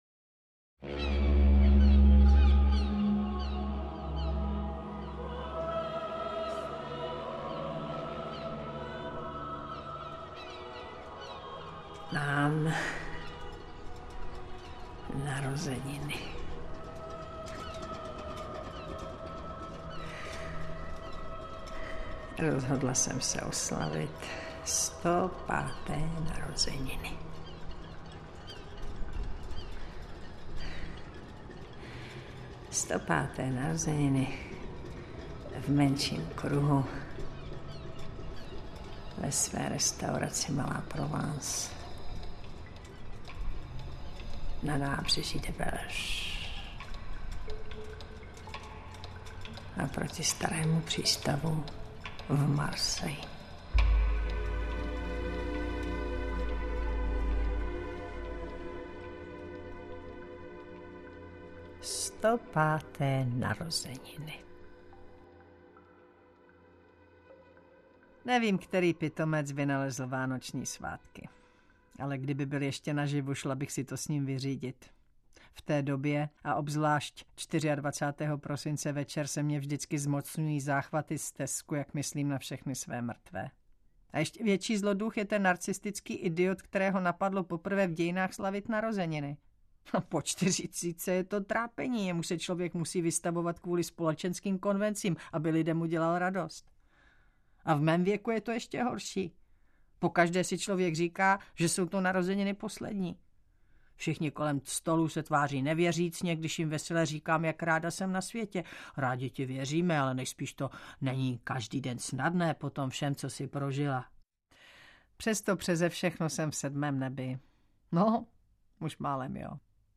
Interpret:  Taťjána Medvecká